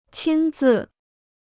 亲自 (qīn zì): personally; in person.